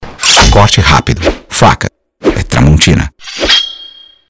knife6.wav